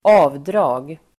Uttal: [²'a:vdra:g]